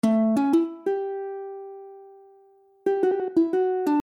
For this song, we want to convey a slow, chill groove.